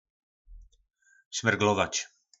Slovník nářečí Po našimu
Šmirglovat (brousit smirkovým papírem) - Šmyrglovač